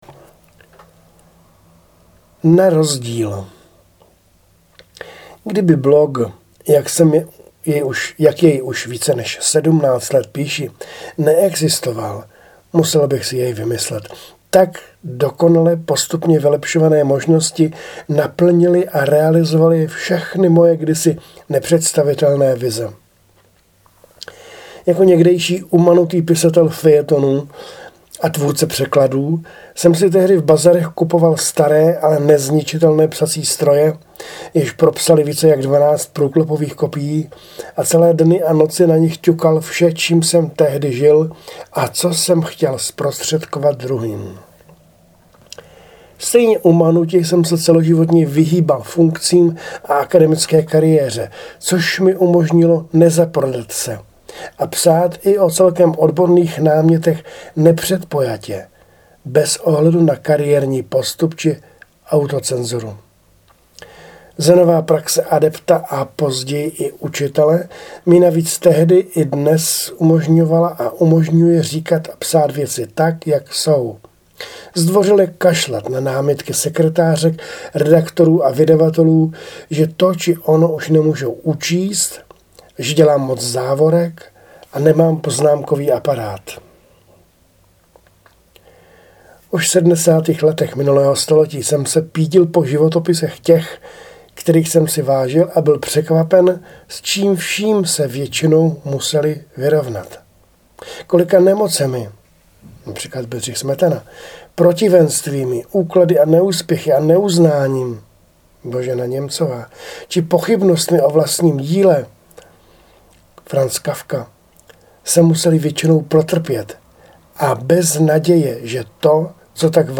Autorsky namluvený fejeton